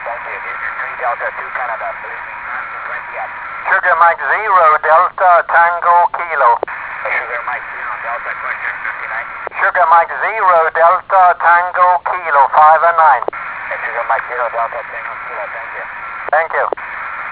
QSO with 3D2C in Fidji Island 27 September 2012 at 09.26 Z.